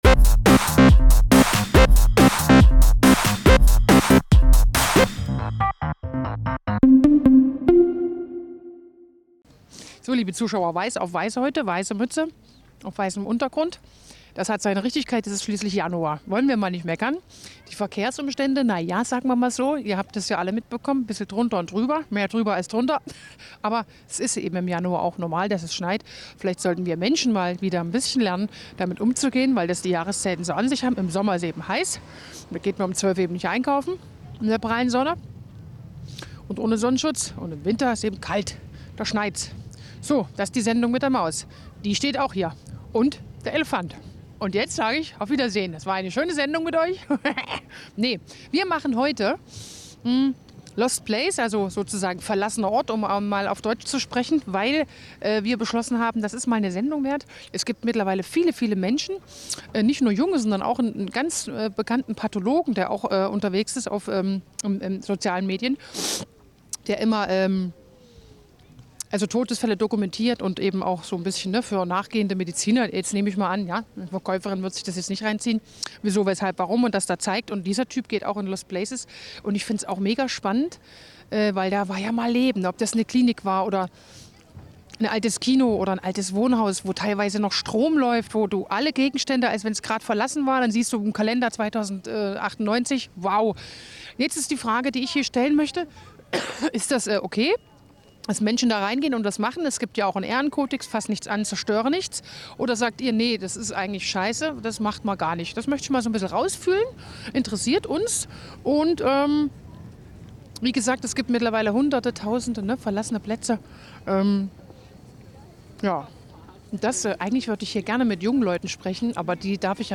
Stra�enumfrage zum Katholikentag in Erfurt